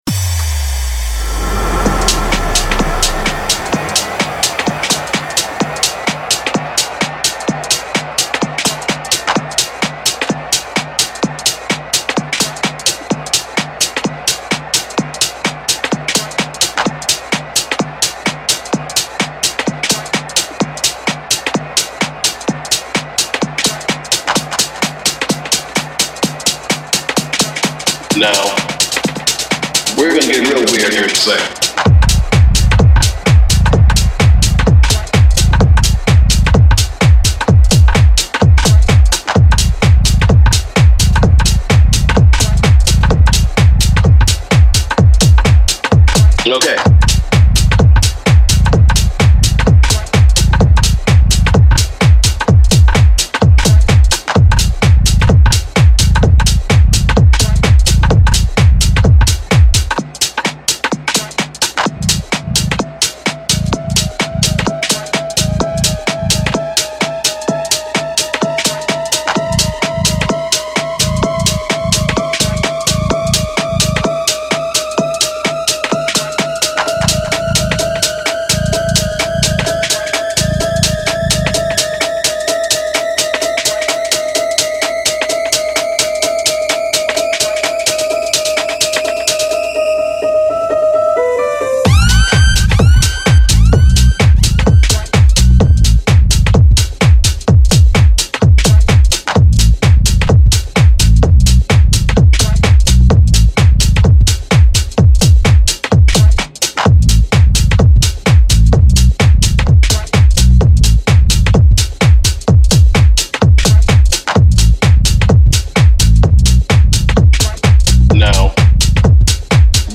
Guaracha
*** / Tech House